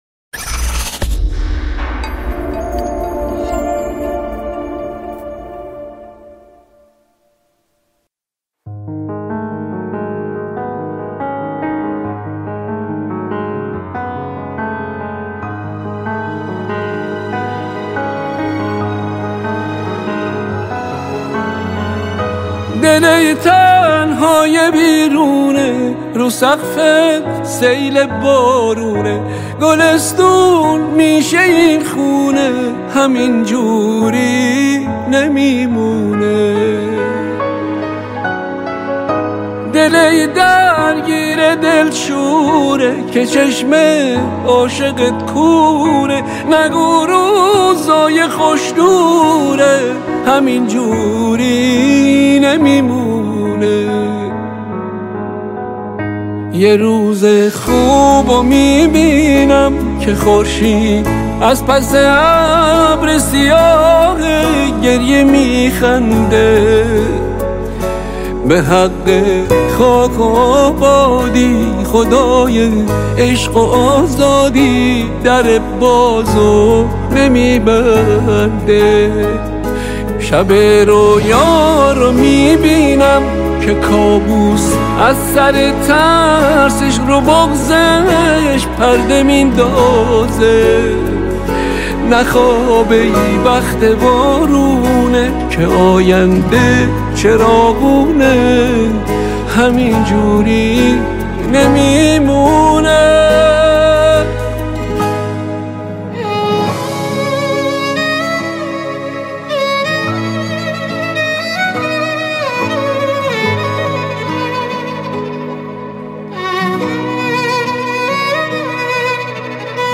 ورژن هوش مصنوعی